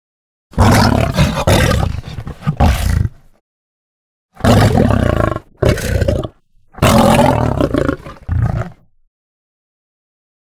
دانلود صدای نعره یا غرش شیر 1 از ساعد نیوز با لینک مستقیم و کیفیت بالا
جلوه های صوتی